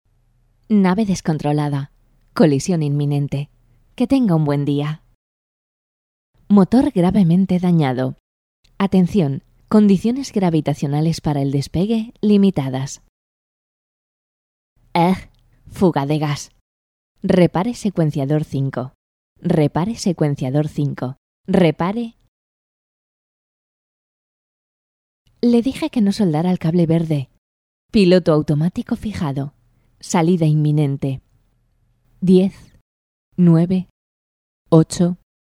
Locución
Campaña publicitaria para el canal de televisión Kisstv.